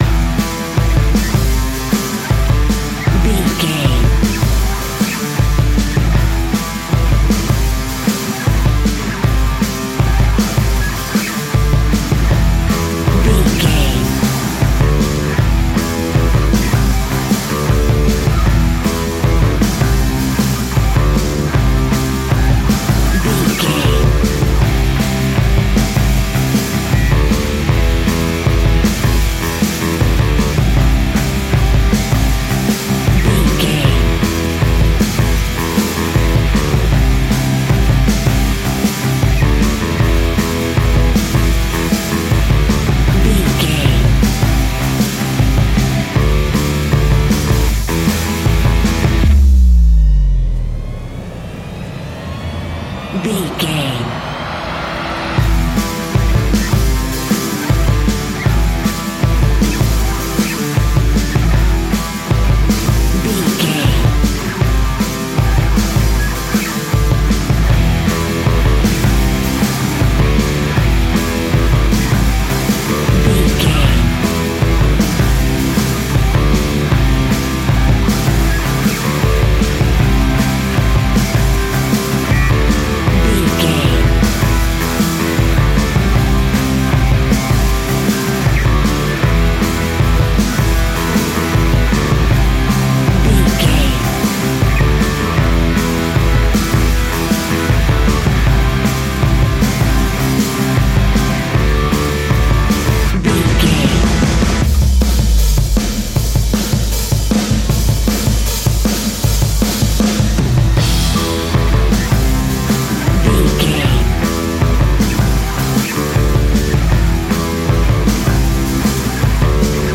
Ionian/Major
A♭
guitars
hard rock
heavy rock
distortion
instrumentals